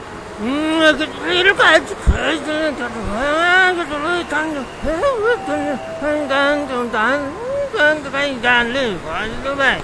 Tag: 纹理 机场 郯子 新山一